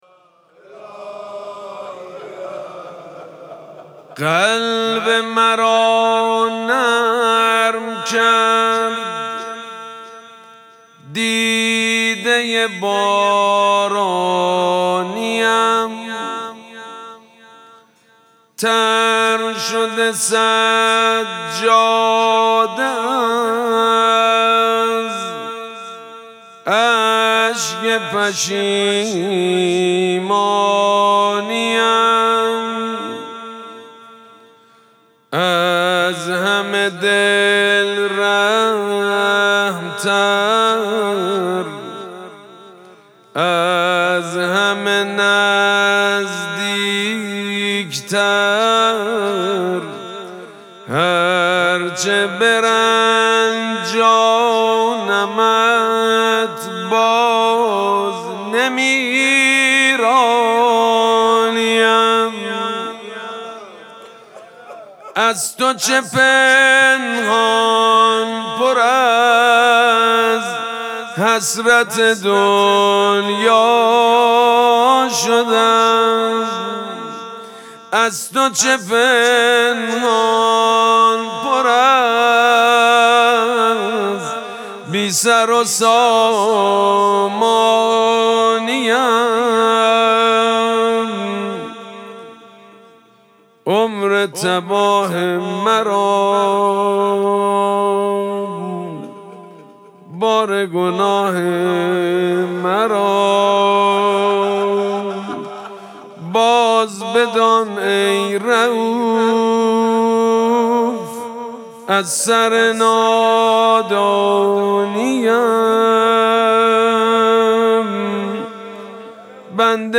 مراسم مناجات شب سوم ماه مبارک رمضان
مناجات